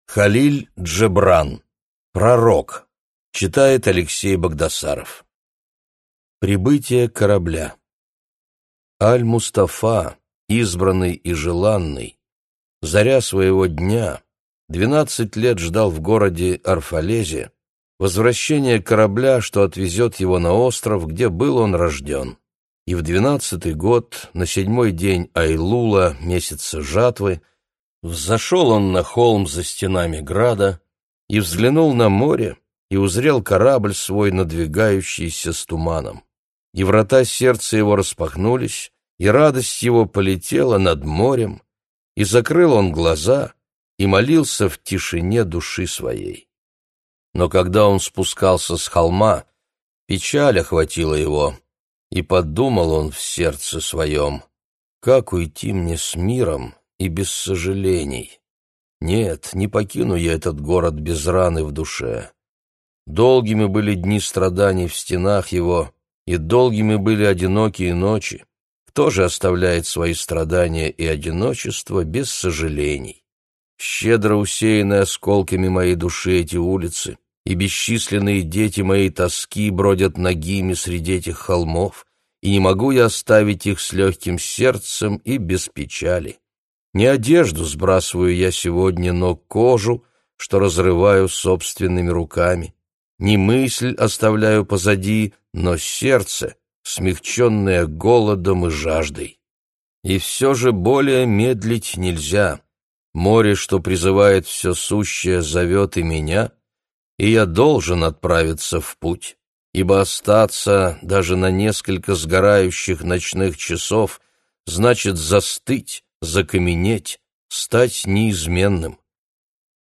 Аудиокнига Пророк | Библиотека аудиокниг